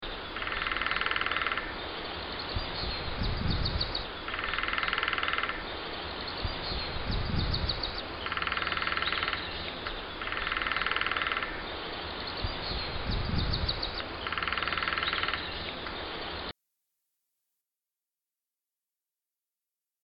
As usual the best time is from February to May, when they are drumming, they are inconspicuous at other times.
recording of their drumming(196KiBs).
LSWdrum.ogg